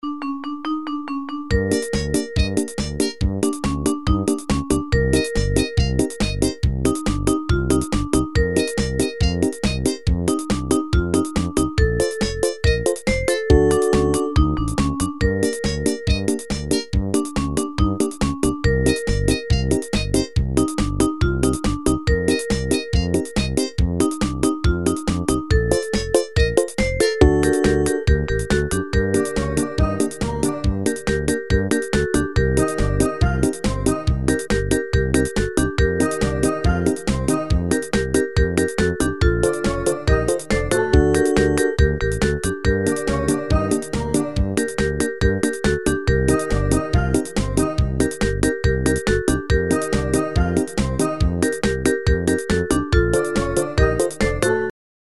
• Качество: 128, Stereo
инструментальные
смешные